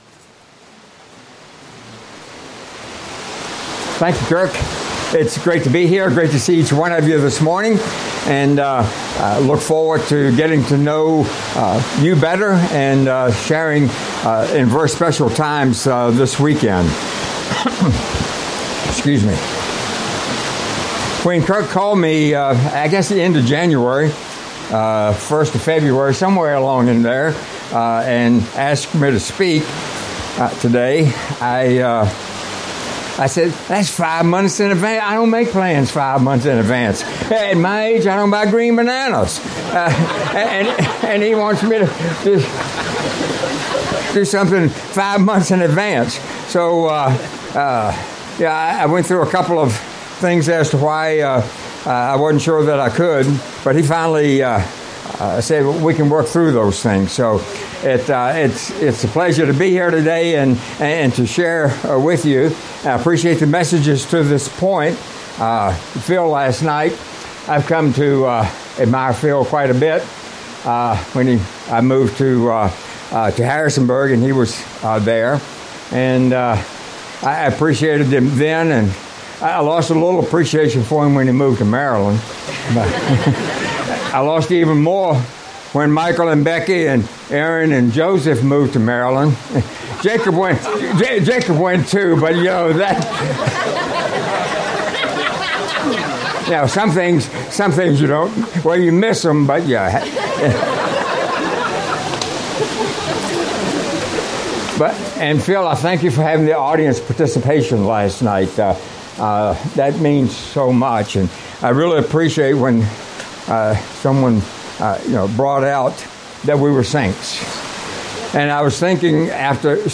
Series: Badlands Family Camp 2022 Passage: Philippians 1:7,12-14; 4:15-19 Audio